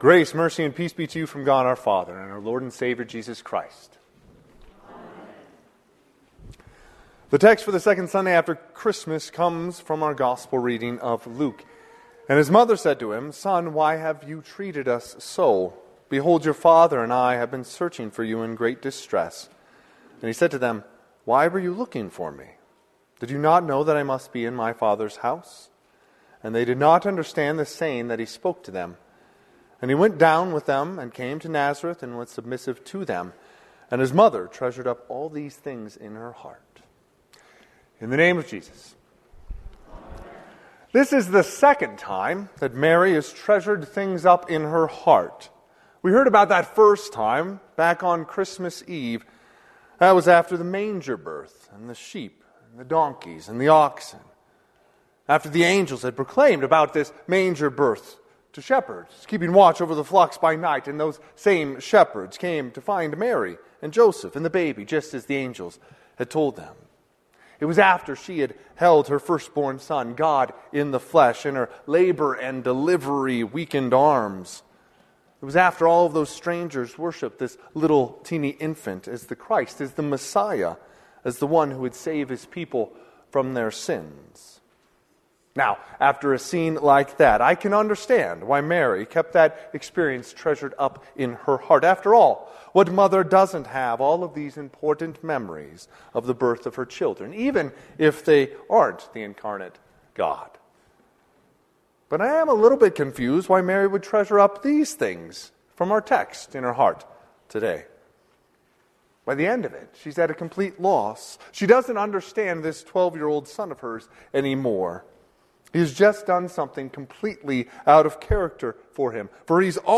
Sermon - 1/5/2025 - Wheat Ridge Lutheran Church, Wheat Ridge, Colorado
Second Sunday after Christmas